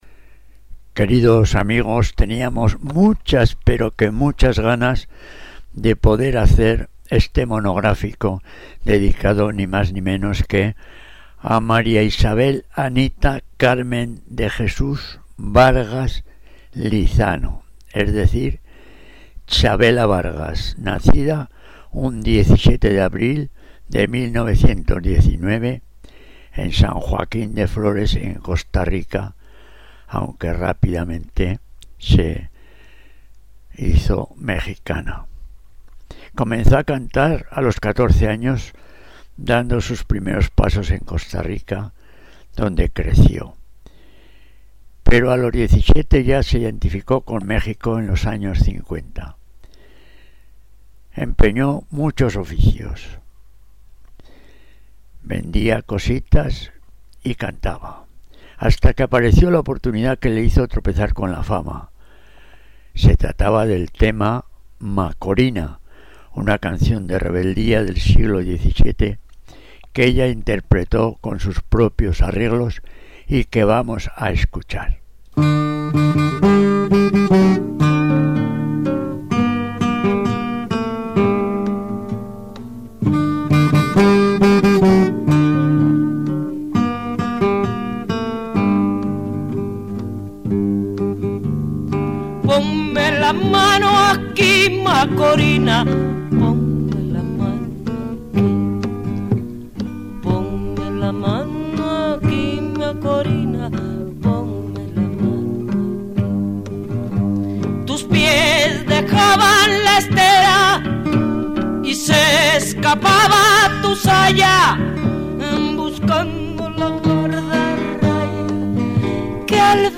Canción Ranchera